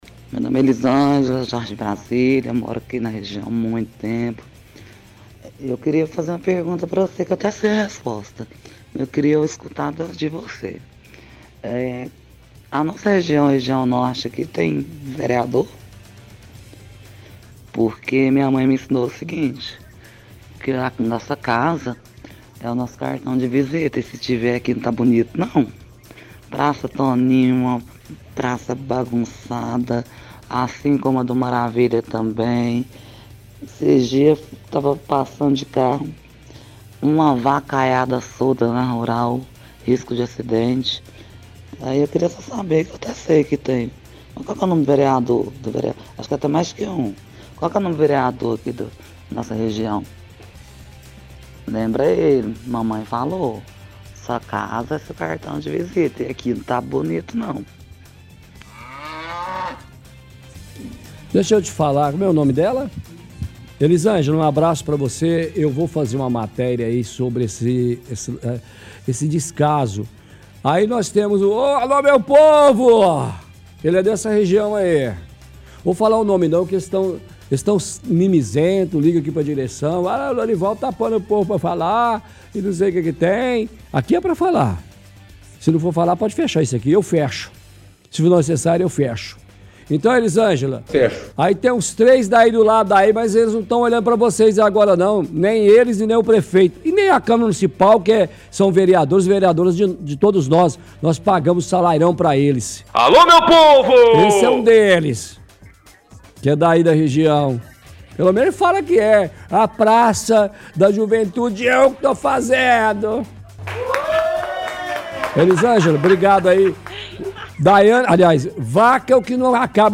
– Ouvinte fala que não tem vereador que represente os bairros Maravilha e Jardim Brasília, diz que tem vacas soltas nas ruas e avenidas. Pergunta o nome do vereador da região, “aqui não está bonito não”.